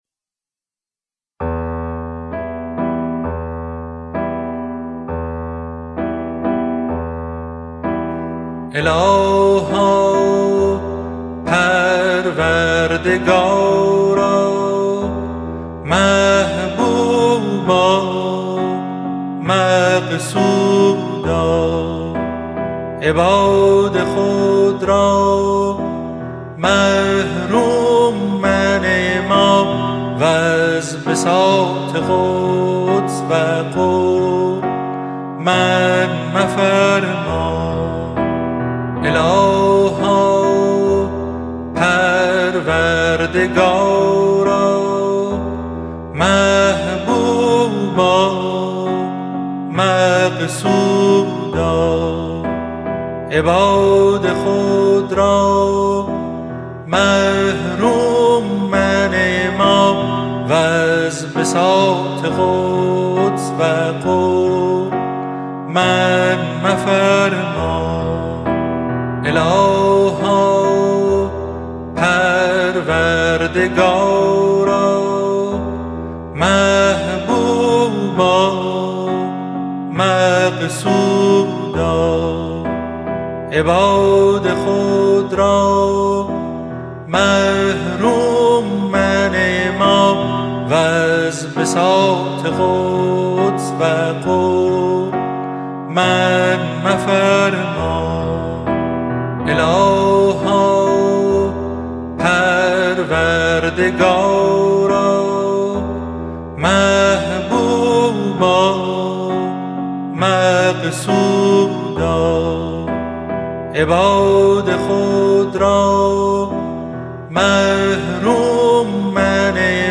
اذکار فارسی (آوازهای خوش جانان)